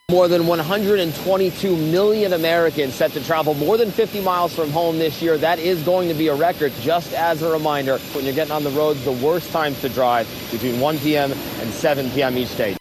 Millions of people will be hitting the road – or taking to the skies – to get to their destinations for the holidays. ABC's Matt Rivers, on the tarmac at LaGuardia Airport in New York, says travel records could be shattered this holiday season…